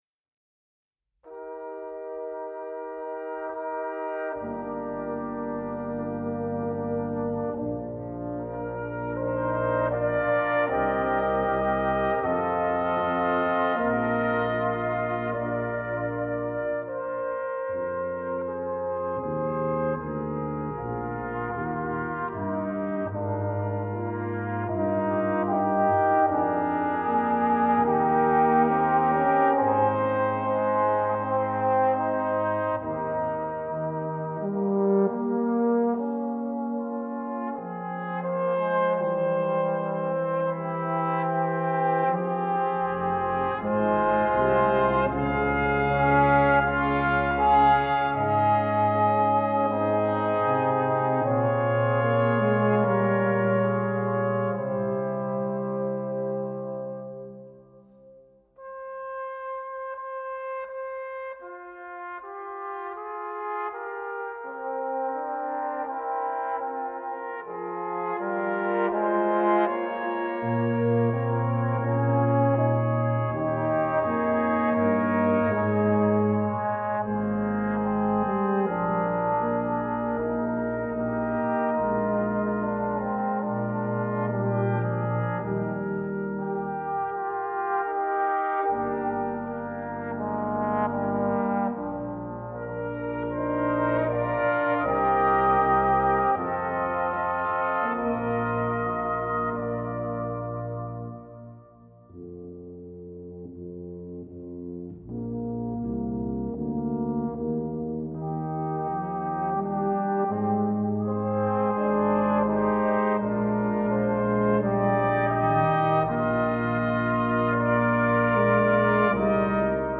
Besetzung: Ensemblemusik für 5 Blechbläser
1. Stimme: Kornett in B (Flügelhorn/Trompete)
4. Stimme: Euphonium in C/B (Posaune)
5. Stimme: Tuba in C/B
Brass Quintett